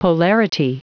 Prononciation audio / Fichier audio de POLARITY en anglais
Prononciation du mot : polarity